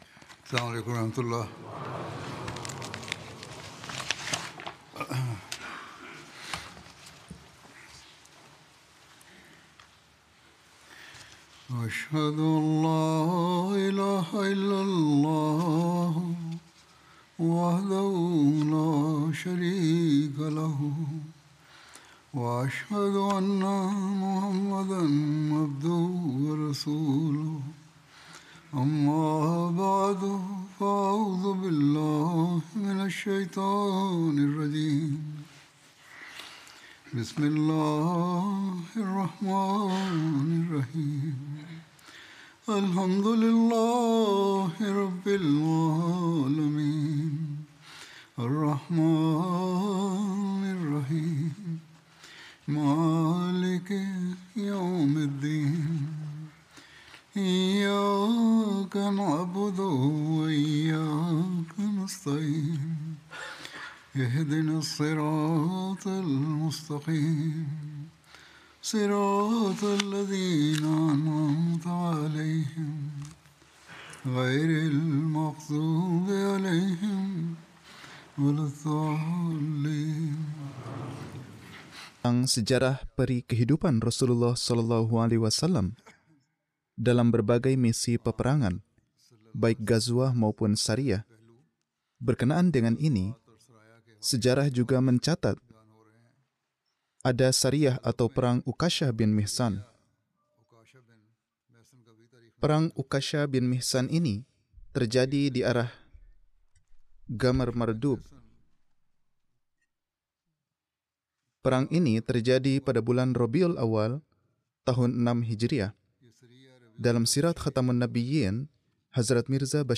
Indonesian translation of Friday Sermon